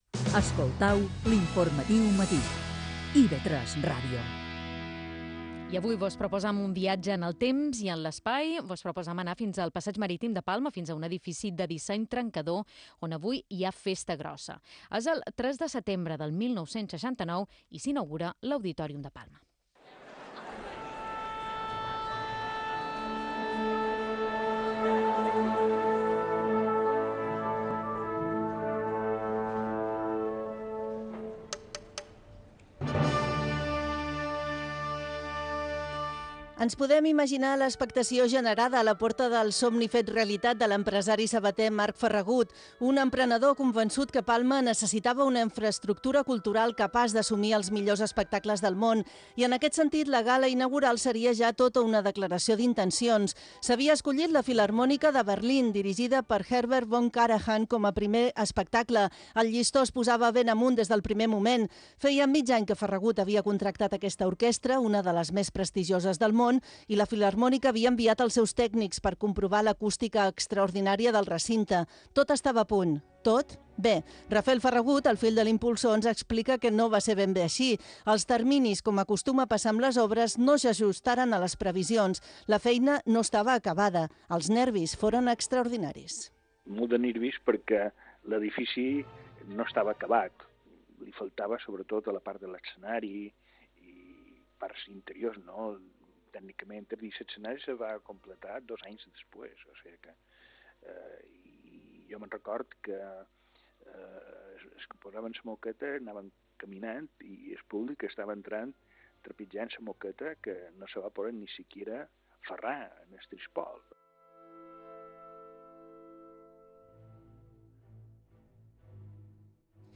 Escolta aquí la peça completa d'IB3 Ràdio